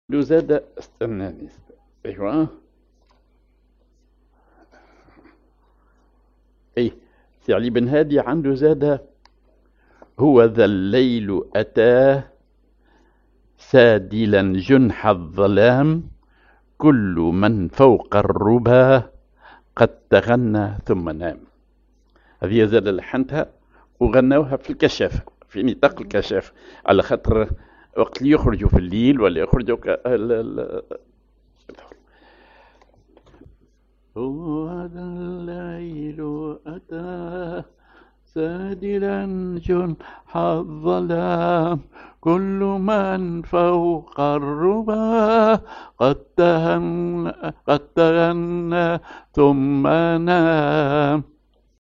Maqam ar نهوند نوا
genre نشيد